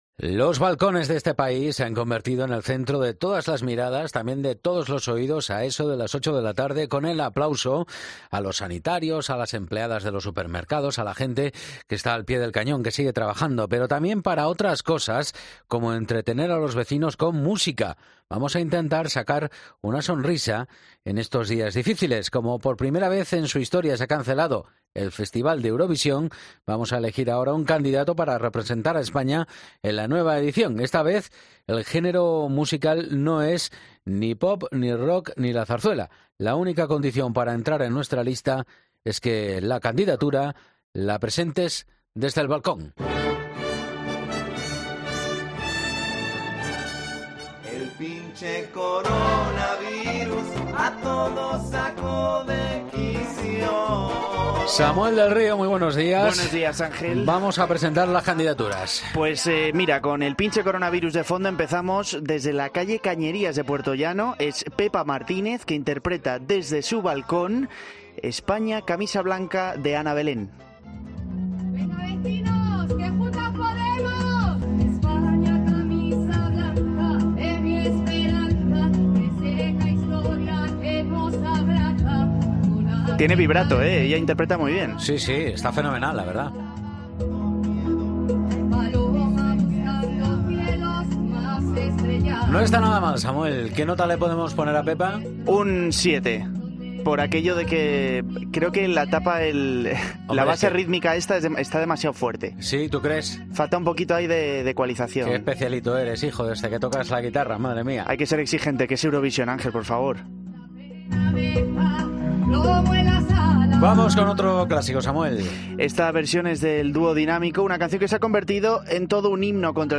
Esta vez el género musical no es pop ni rock ni zarzuela, la única condición para entrar en nuestra lista es que tu candidatura la presentes desde tu balcón.
Escucha cómo contesta al pianista desde su balcón.